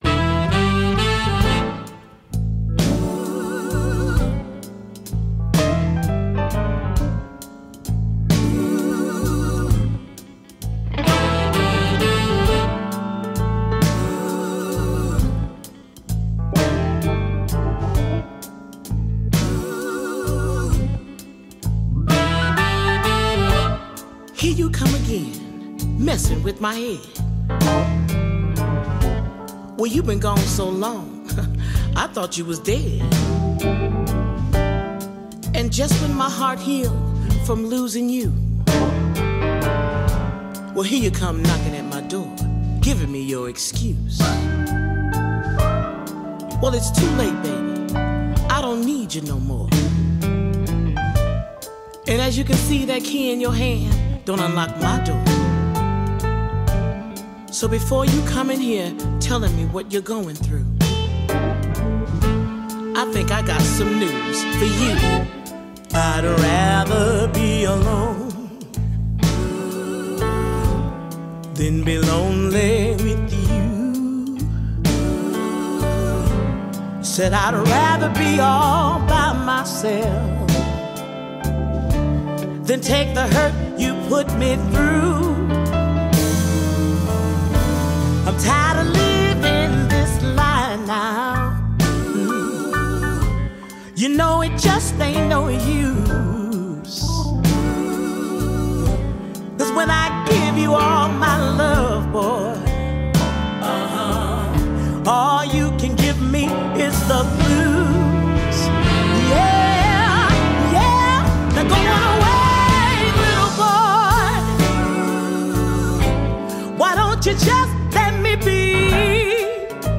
Blues en las ondas, el programa de blues de UPV Radio